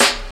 07.3 SNARE.wav